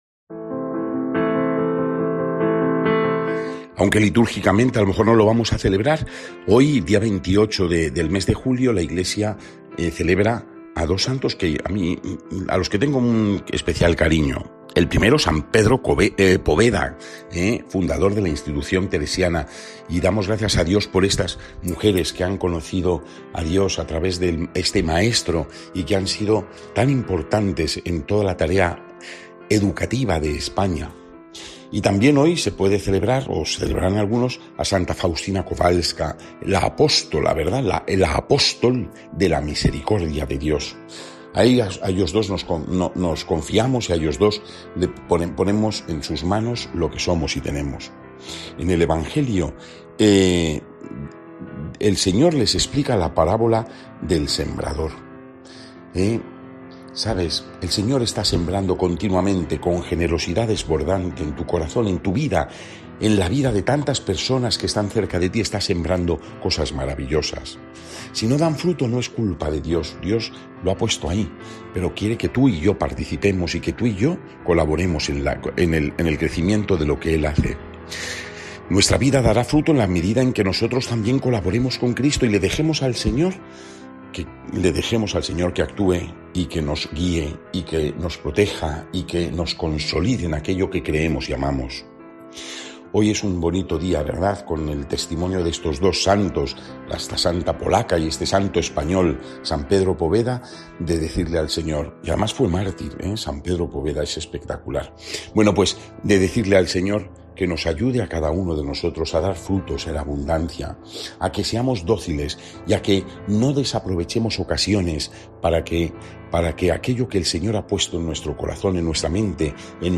Lectura del santo evangelio según san Mateo 13,18-23